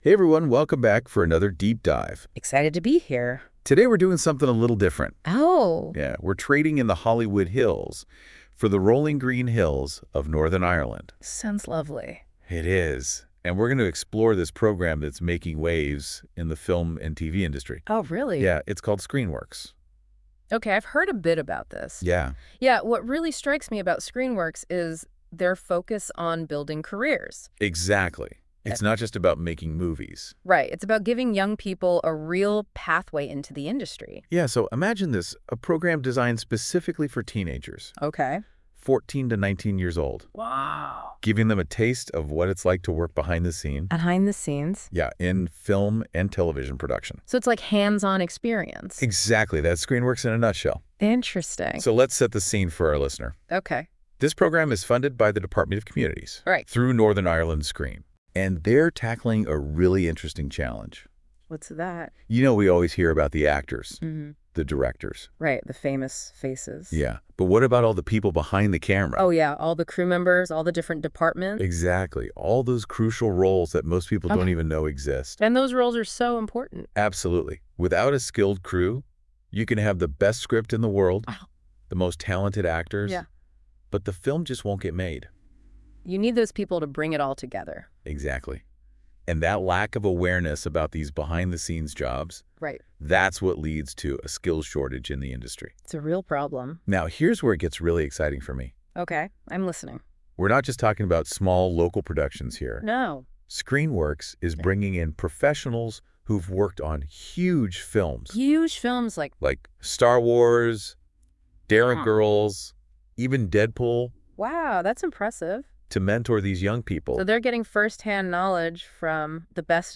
ScreenWorks Evaluation - Google NotebookLM "Podcast"